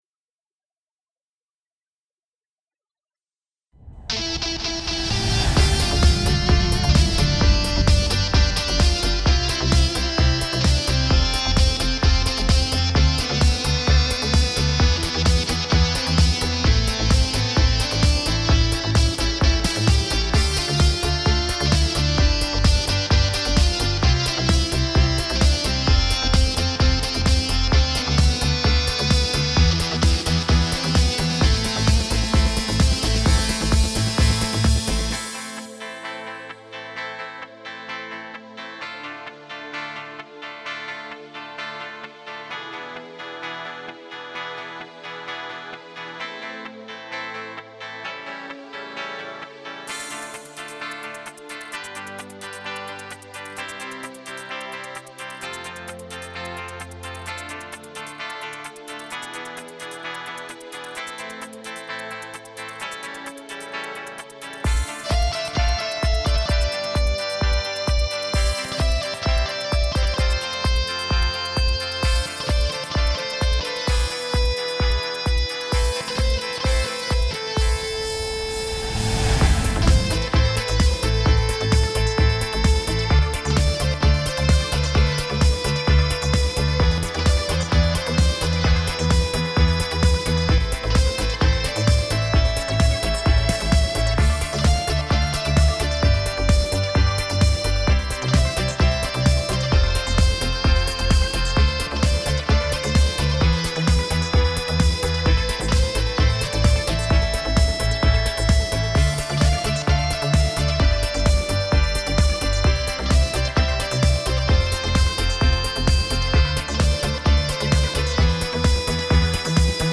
минусовка версия 236297